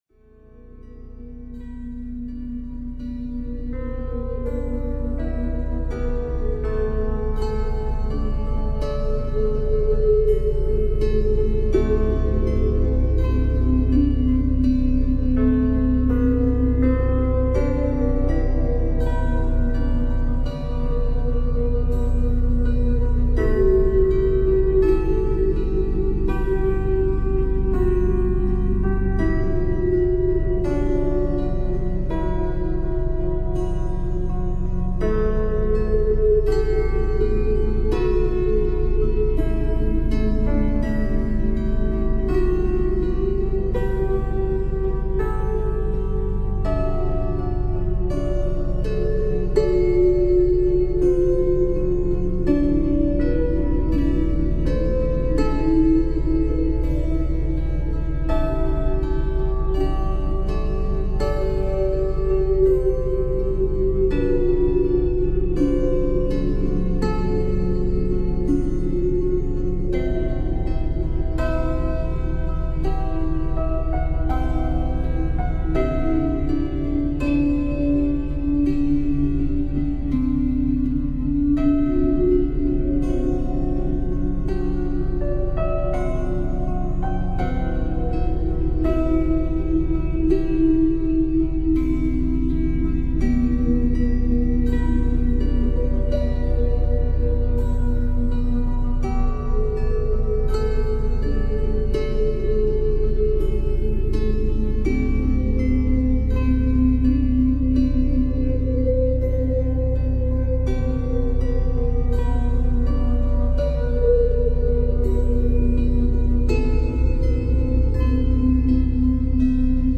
Delta Wave Meditation – Sleep, Relaxation, and Pain Relief